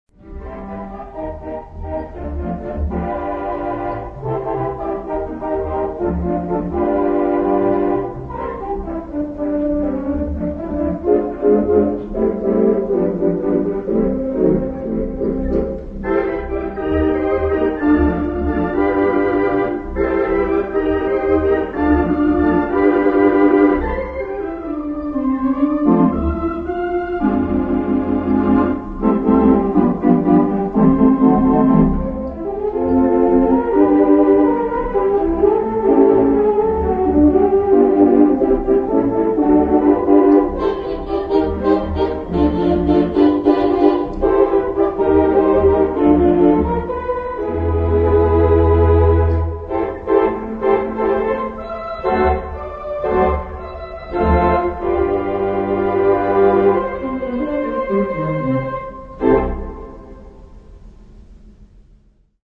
Instrument: 2/10 Wurlitzer OPUS 1733